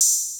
{OpenHat} TTLN.wav